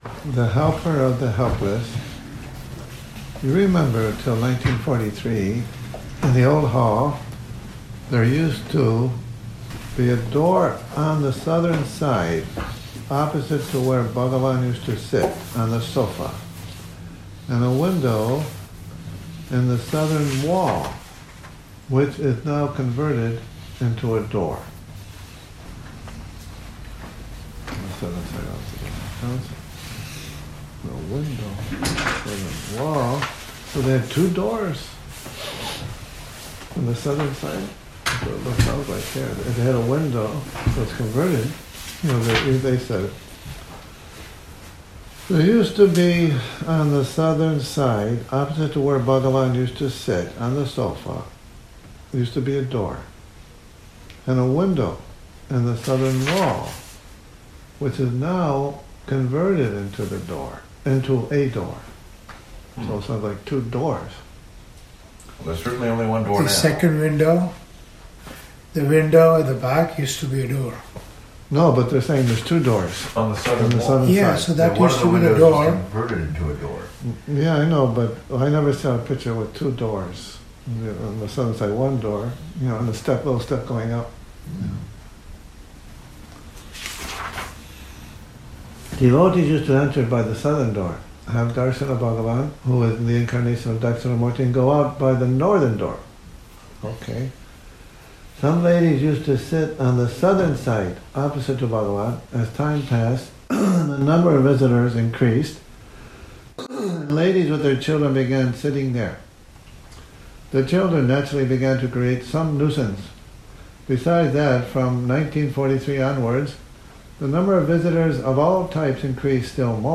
Morning Reading, 09 Sep 2019